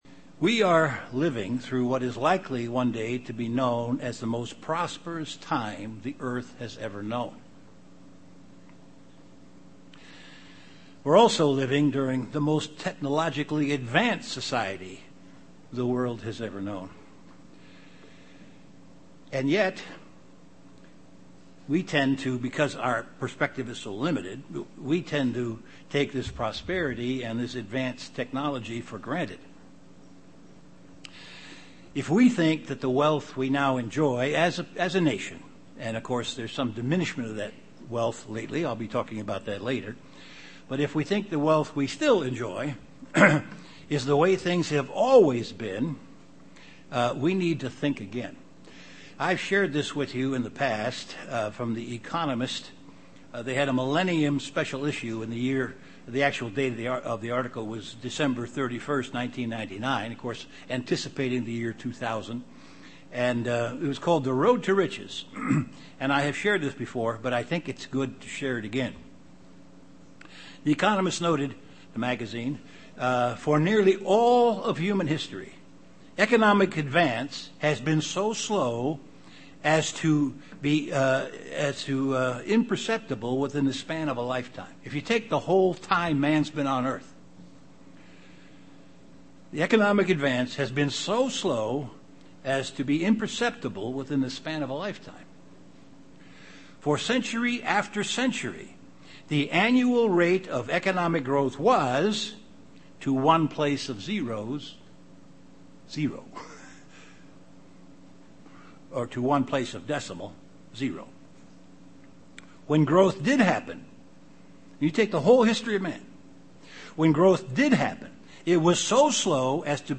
Given in Chicago, IL
UCG Sermon Studying the bible?